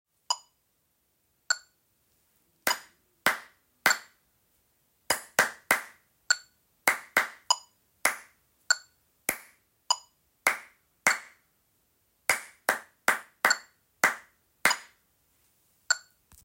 7- Select the option that corresponds to this 2/4 clapping sound.